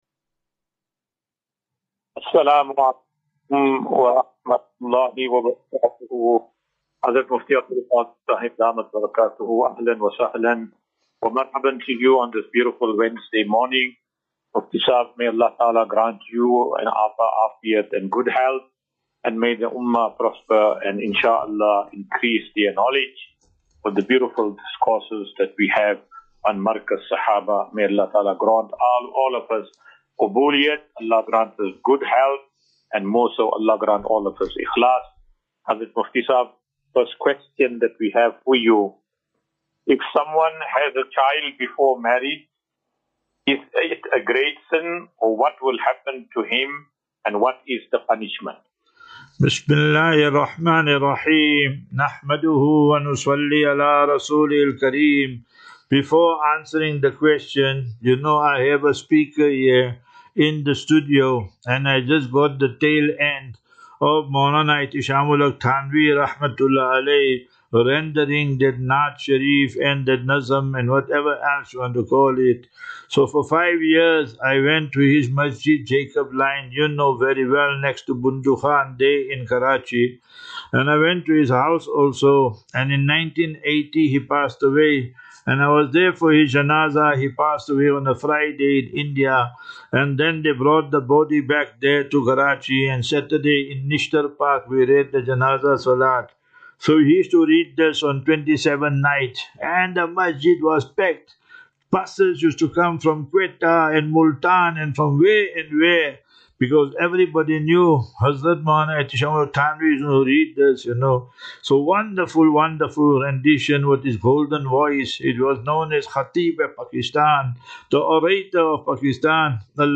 View Promo Continue Install As Safinatu Ilal Jannah Naseeha and Q and A 5 Mar 05 March 2025.